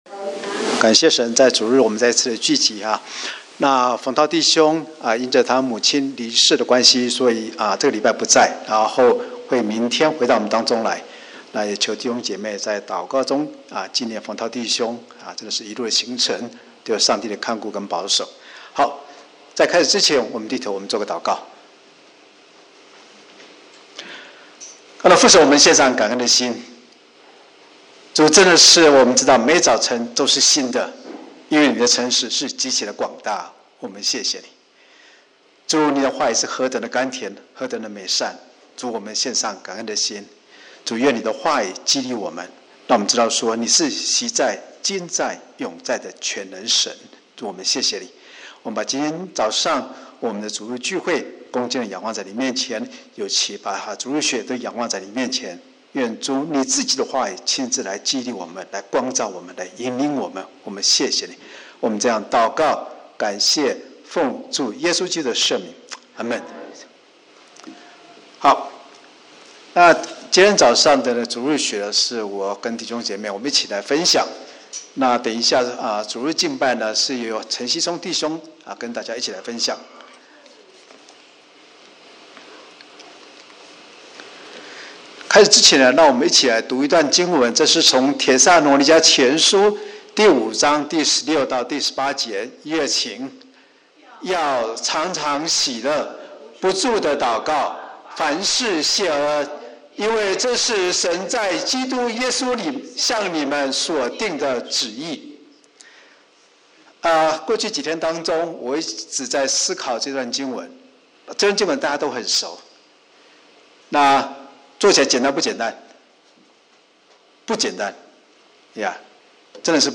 Sunday School 主日学